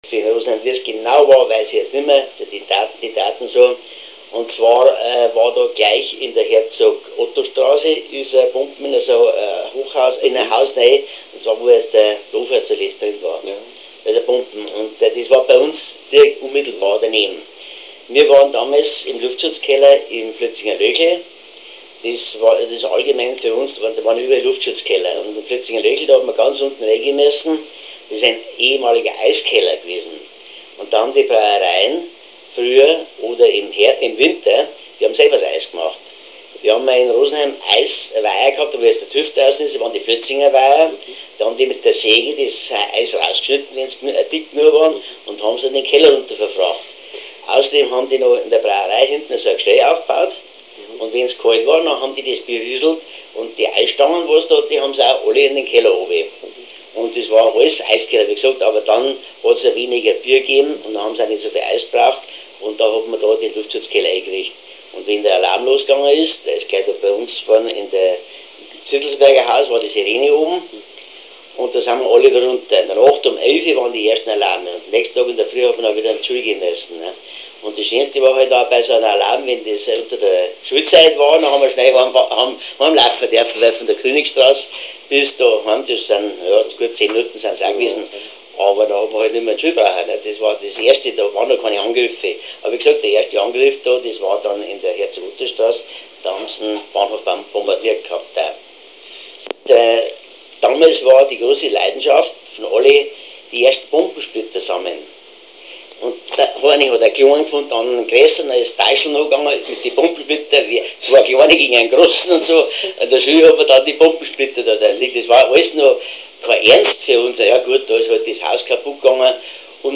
Zeitzeugen